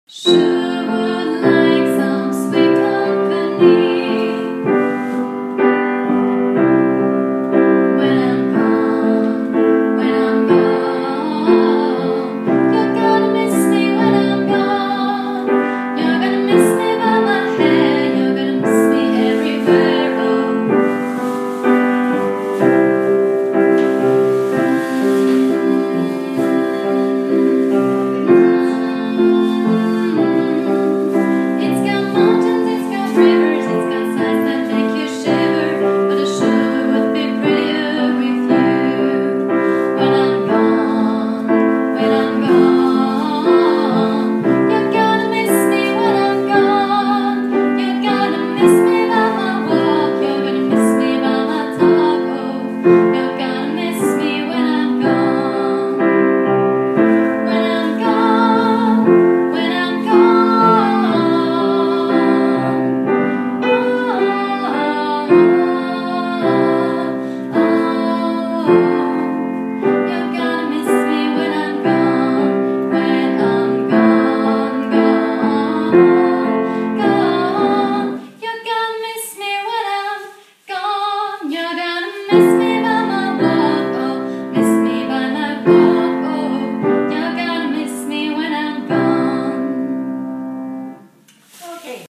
Übungsdatei 2. Stimme
6_cups_2stimme.mp3